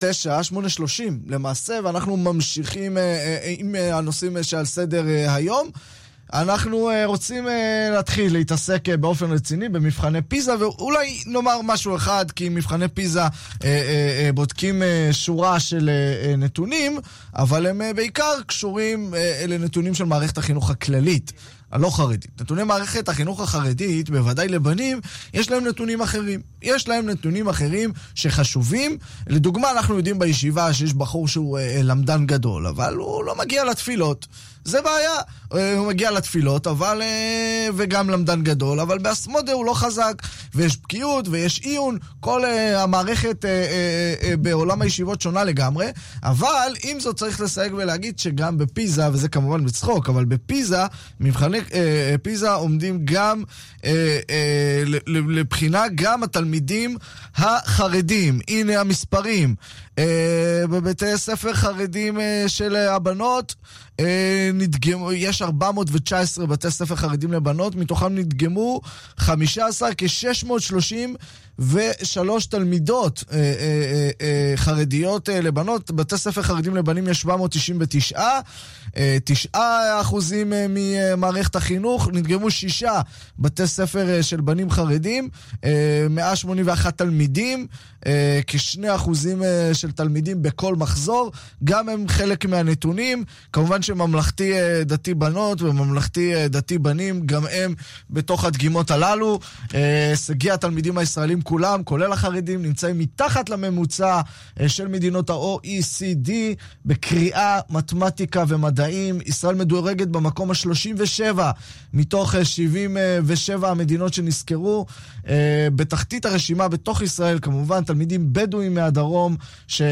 ראיון בתחנת הרדיו "כאן מורשת"